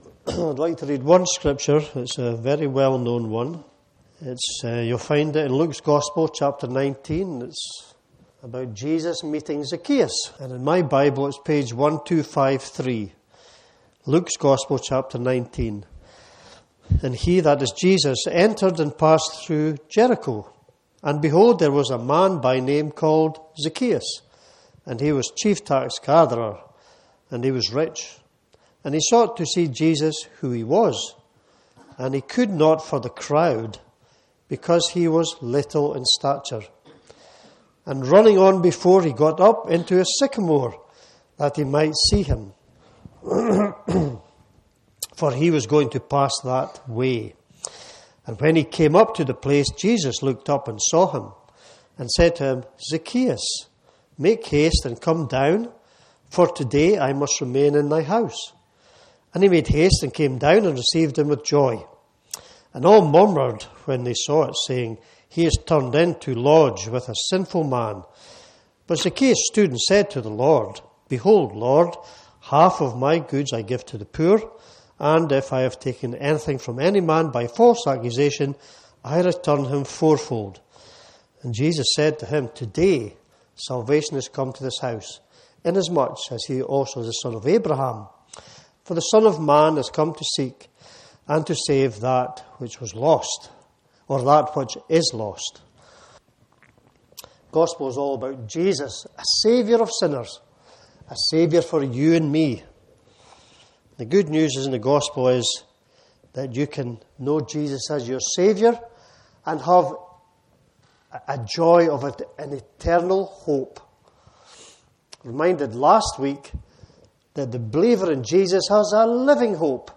This Gospel preaching looks at the story of Zacchaeus, the tax collector who met Jesus and was never the same again.